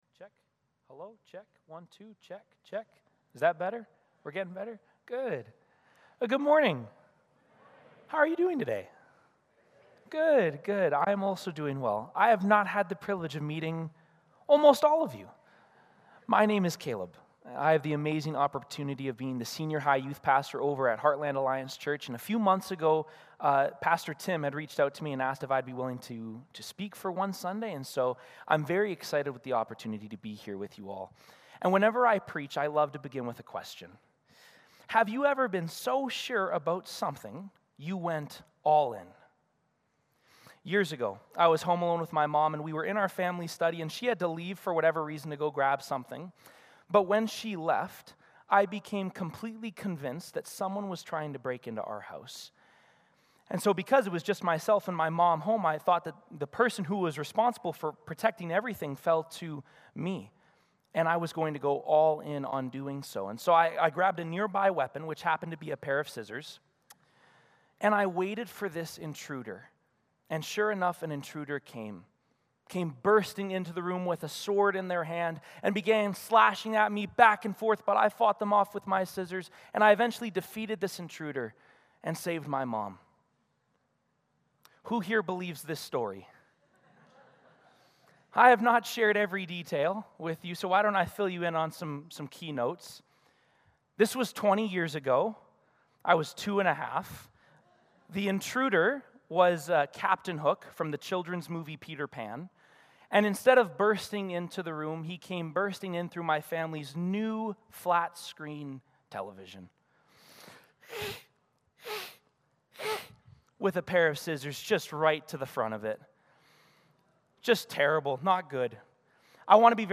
James 2:14-19 Service Type: Sunday Morning Service Passage